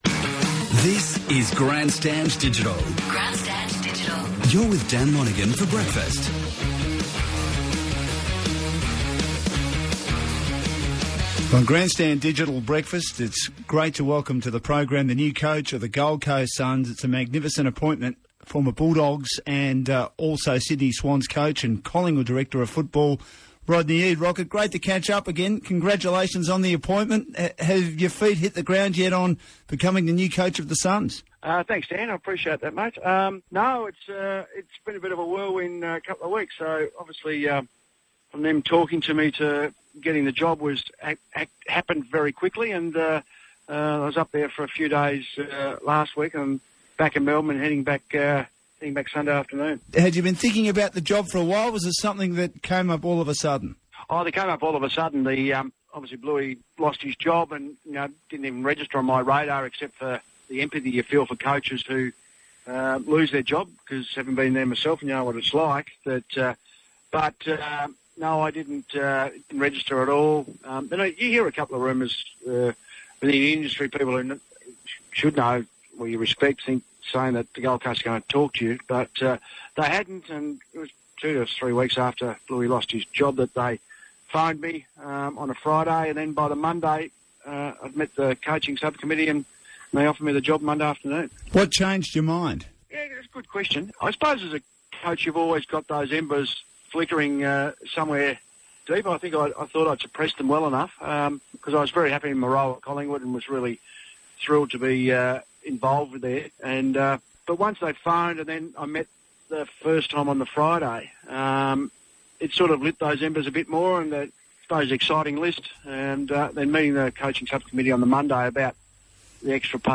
Senior coach Rodney Eade speaks with ABC Grandstand.